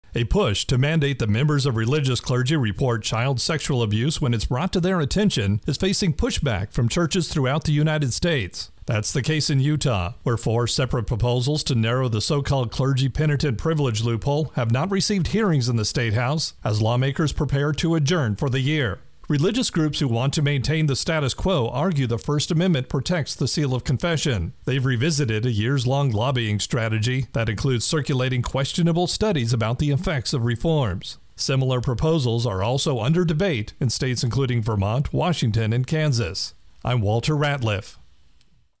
Intro and voicer for Clergy Penitent Privilege.